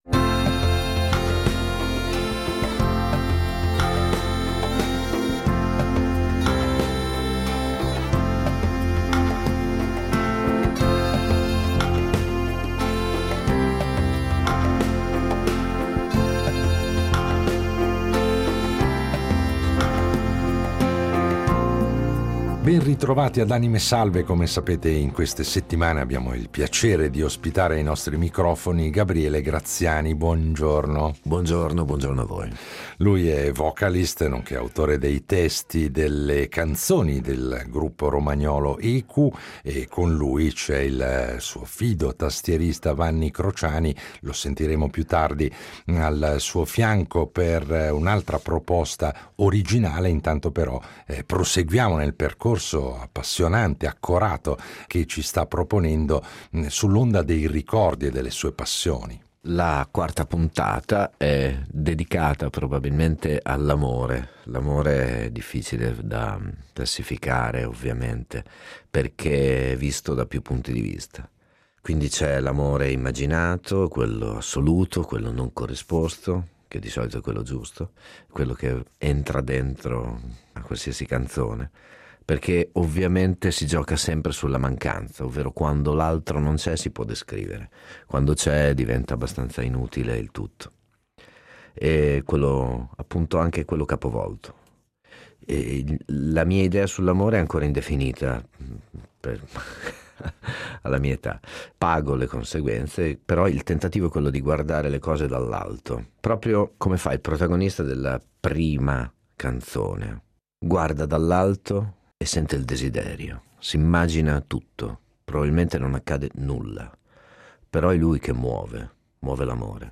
Cinque puntate per scoprire passioni e pensieri di un cantautore che, come sempre in "Anime Salve", ci regala alcune sue canzoni interpretate dal vivo.